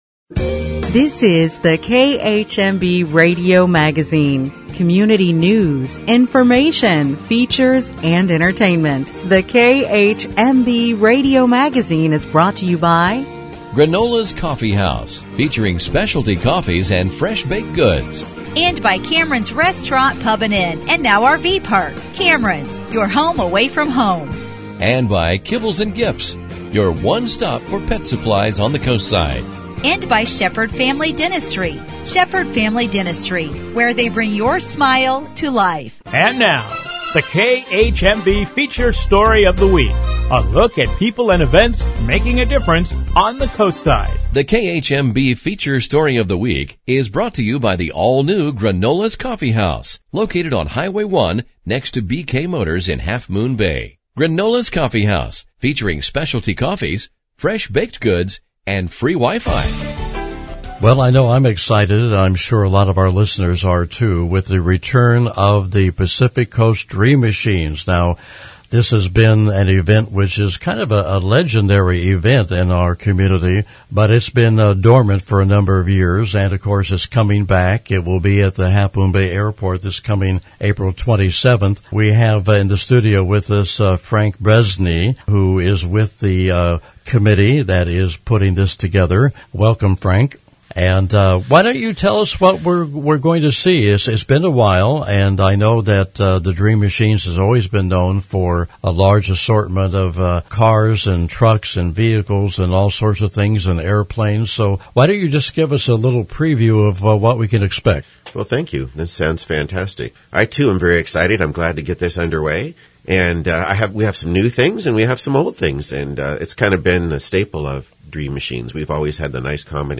Each week in our Feature Segment the station will highlight an individual making a difference in our community or news story of importance. In addition there will be a traditional newscast with various stories of interest.